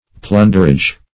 Plunderage \Plun"der*age\, n. (Mar. Law)